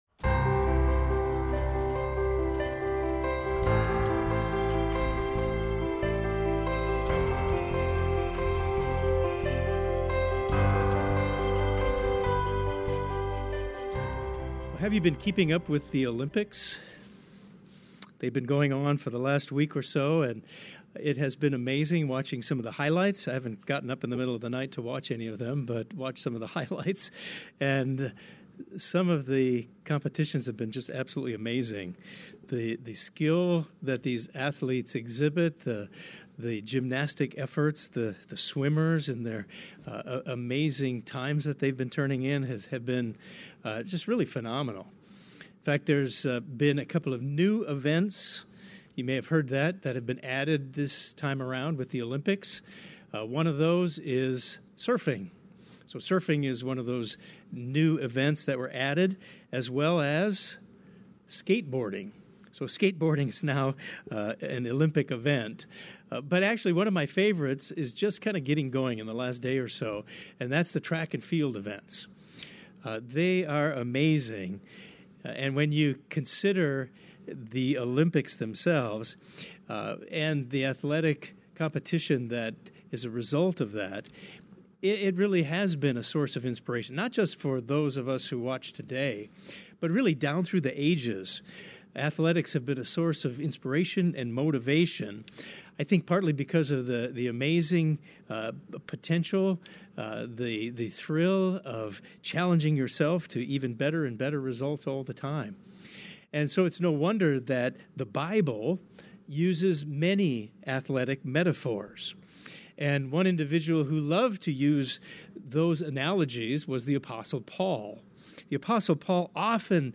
This sermon discusses how can we have the right perspective in this race of our life.